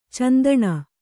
♪ candaṇa